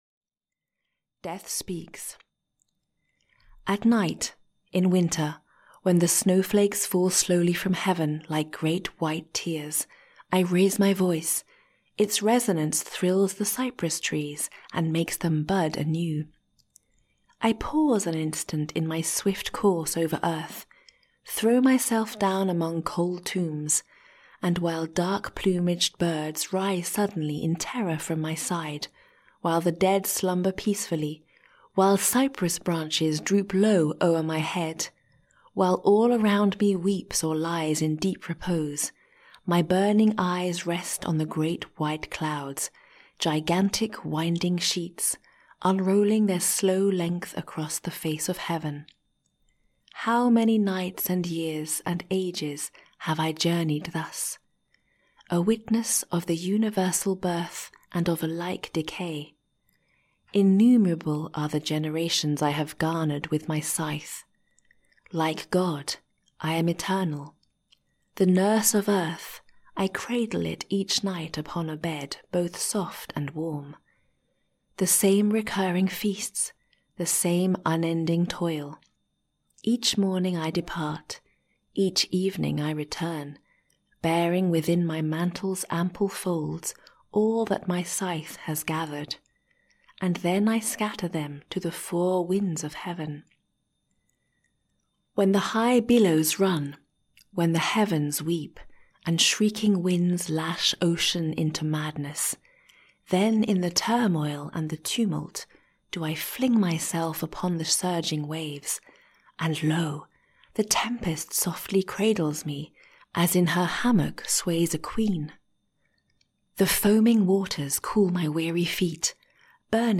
Audiobook 3 French Short Stories by Gustave Flaubert. Herodias, The Dance of death and The Legend of Saint Julian The Hospitalier, 3 french short stories by Gustave Flaubert.
Ukázka z knihy